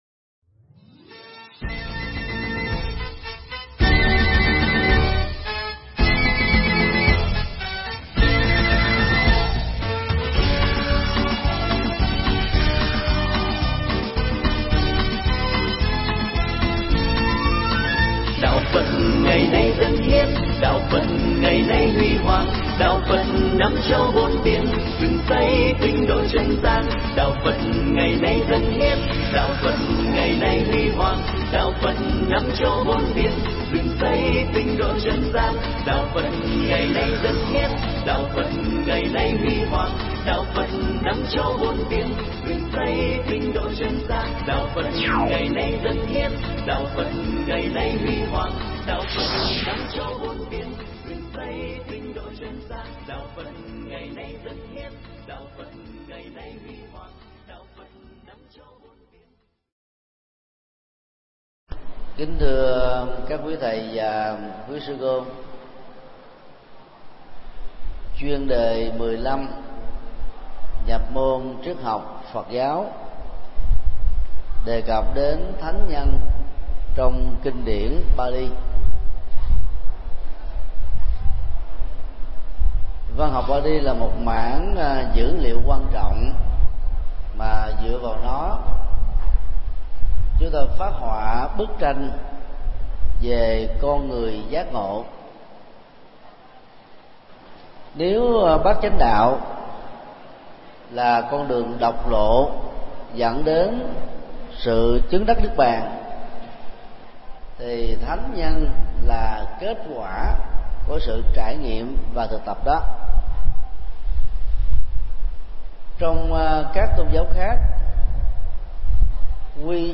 Mp3 Bài giảng Dẫn Nhập Triết Học Phật Giáo 15
giảng tại chùa Phổ Quang